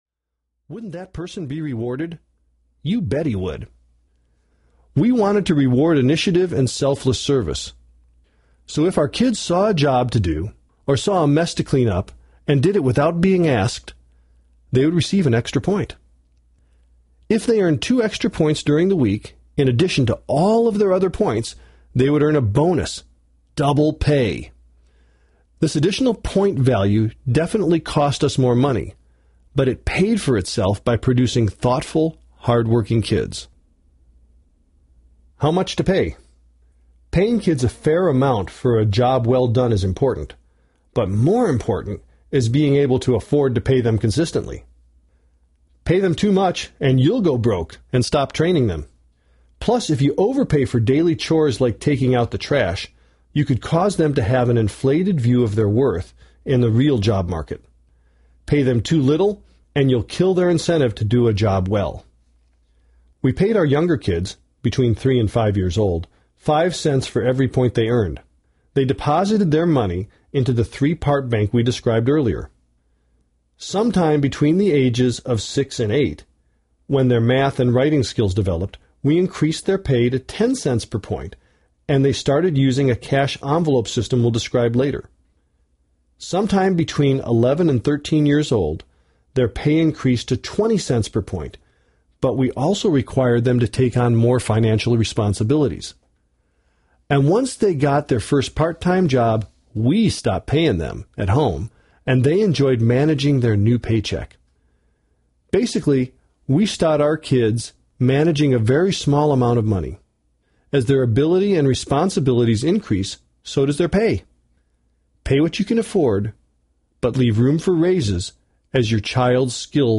The MoneySmart Family System Audiobook
Narrator